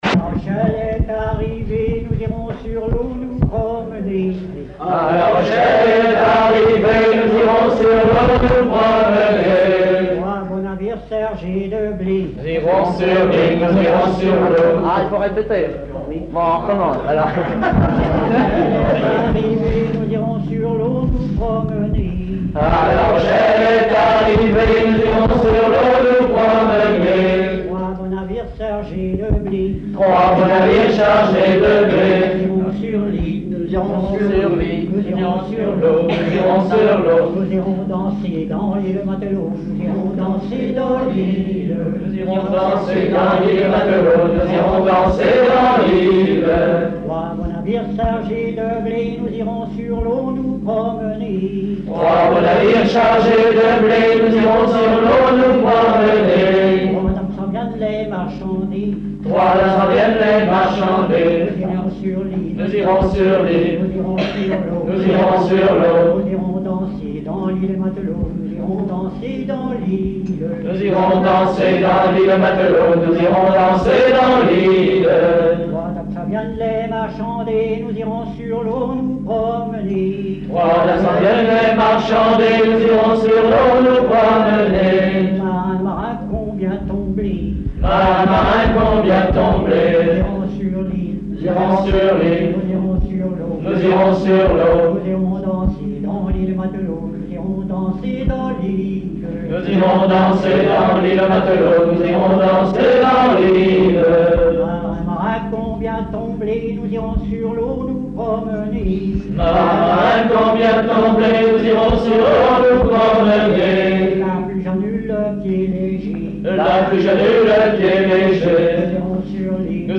Genre laisse
Chansons et commentaires
Pièce musicale inédite